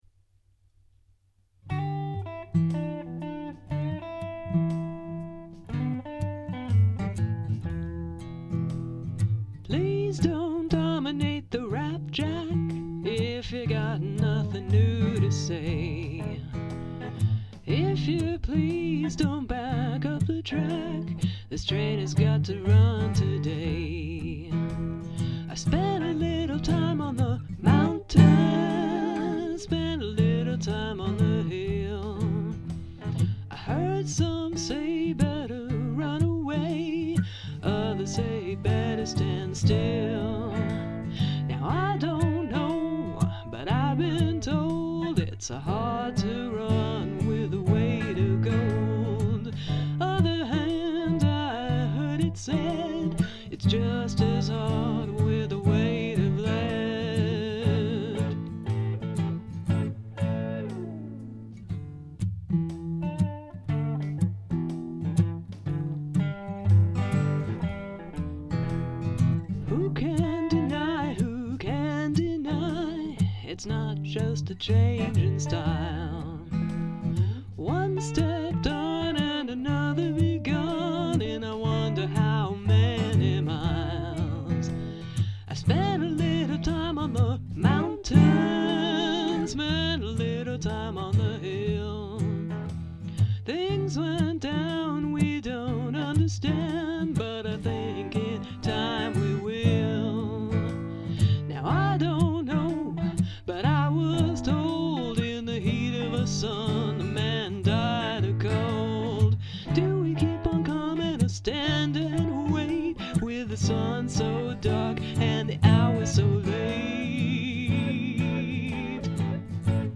classic rock and reggae from the 1970s and the 1980s